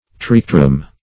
Search Result for " triquetrum" : The Collaborative International Dictionary of English v.0.48: Triquetrum \Tri*que"trum\, n.; pl.
triquetrum.mp3